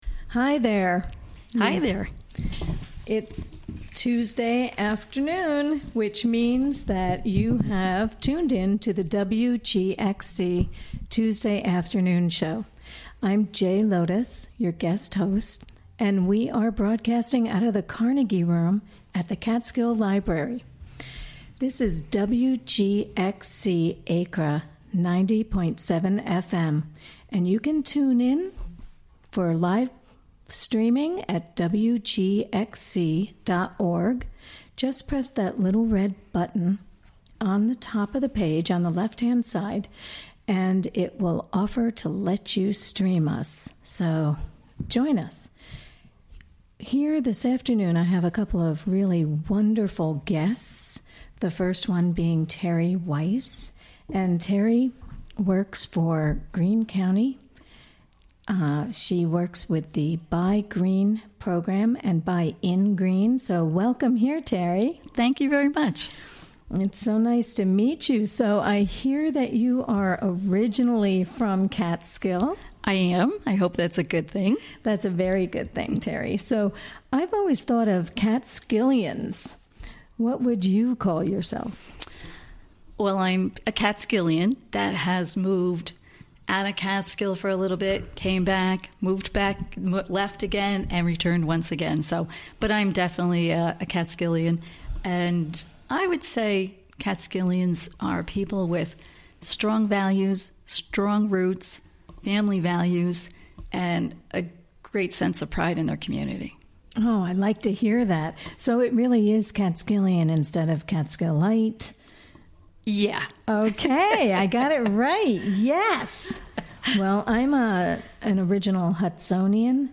Interviewed
Recorded during the WGXC Afternoon Show of Tuesday, Oct. 3, 2017.